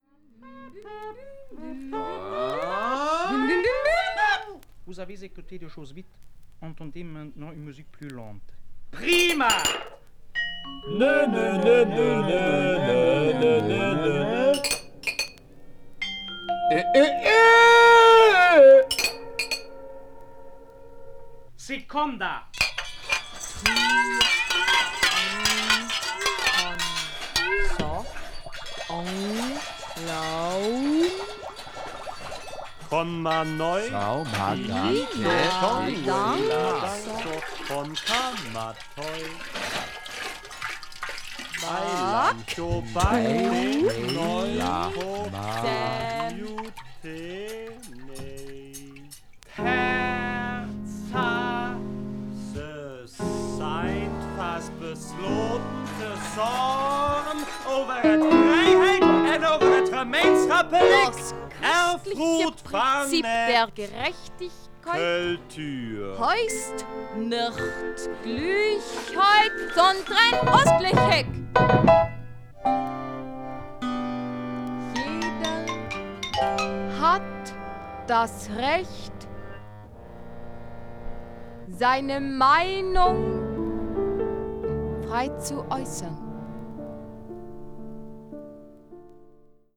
media : EX-/EX-(わずかなチリノイズ/一部軽いチリノイズが入る箇所あり)